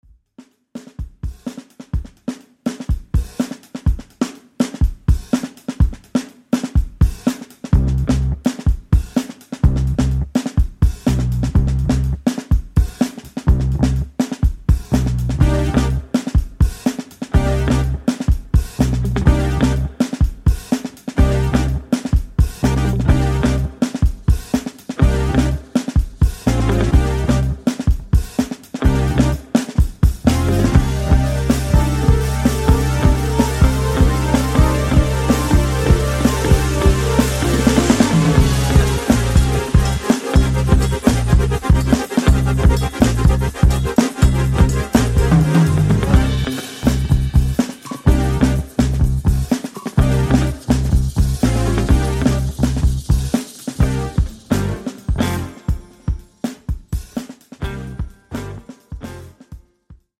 Excellent modern fusion